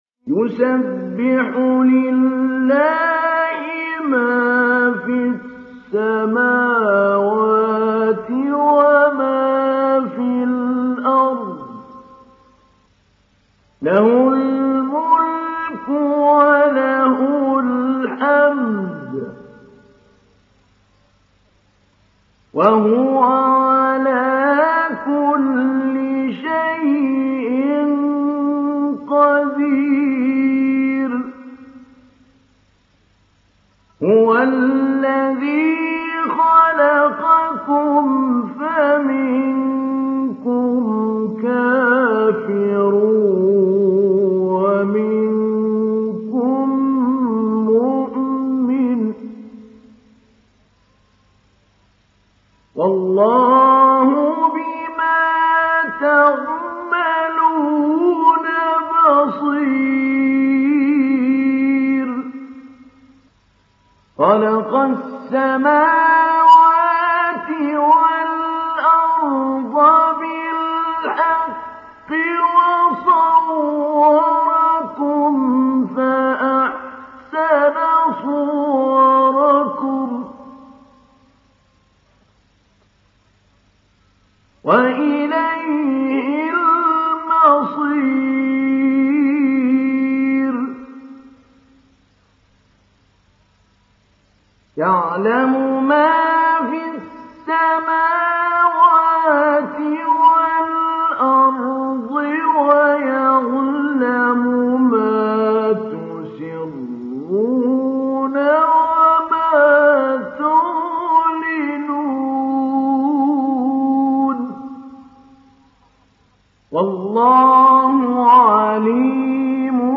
Surah At Taghabun Download mp3 Mahmoud Ali Albanna Mujawwad Riwayat Hafs from Asim, Download Quran and listen mp3 full direct links
Download Surah At Taghabun Mahmoud Ali Albanna Mujawwad